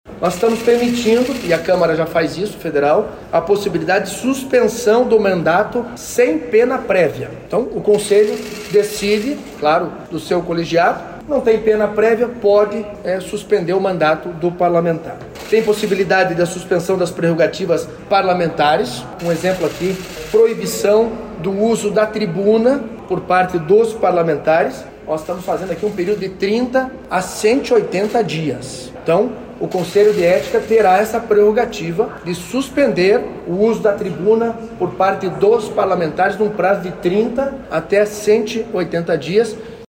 Com o novo Código de Ética, serão adotadas algumas ações mais severas, como pontuou Alexandre Curi.